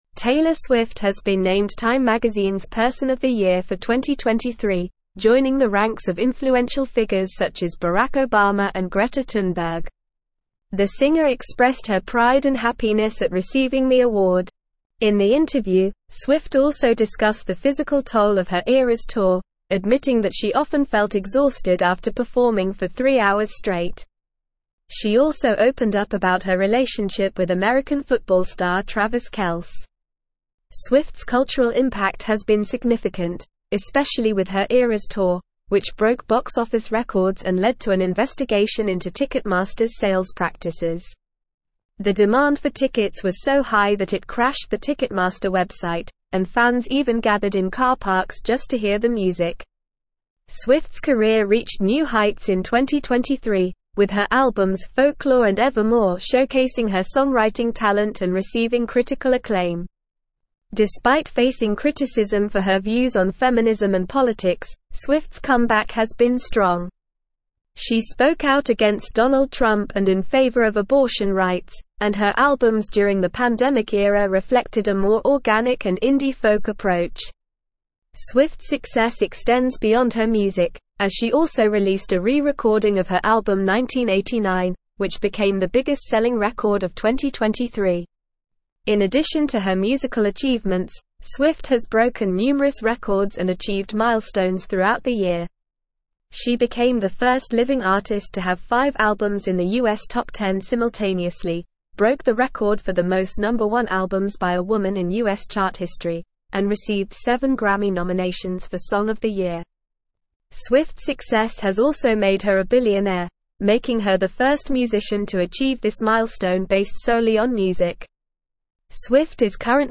Normal
English-Newsroom-Advanced-NORMAL-Reading-Taylor-Swift-Time-Magazines-Person-of-the-Year.mp3